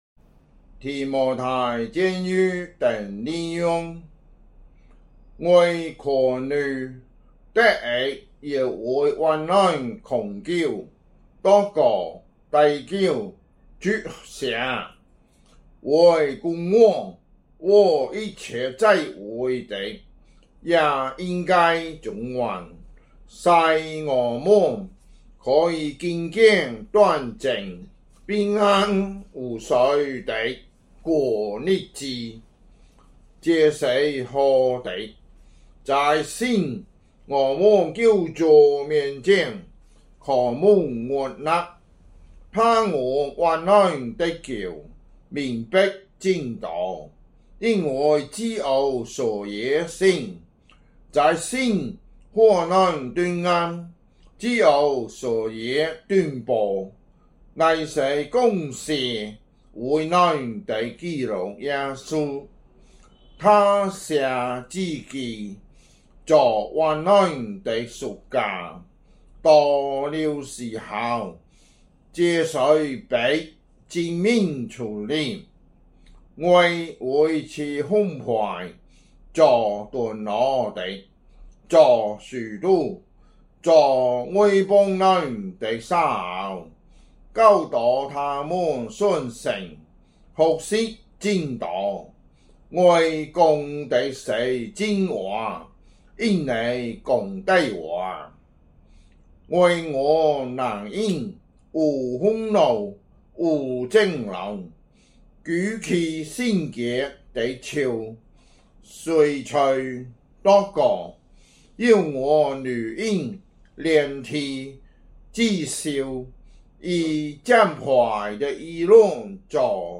福州話有聲聖經 提摩太前書 2章